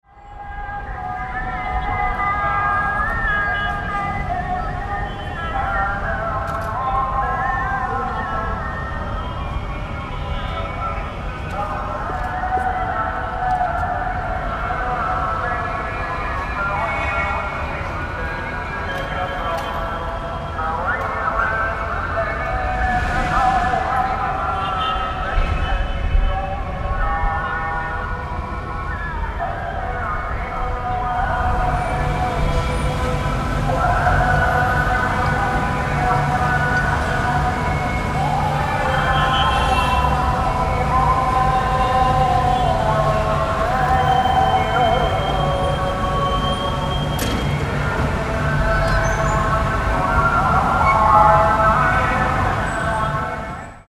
syriast-ambient_call-to-prayer.mp3